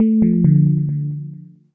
Sons système / System sounds